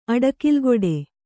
♪ aḍakilgōḍe